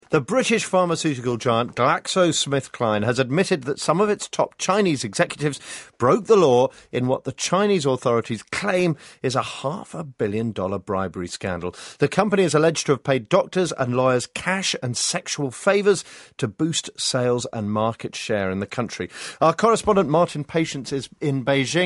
【英音模仿秀】葛兰素史克在华行贿 听力文件下载—在线英语听力室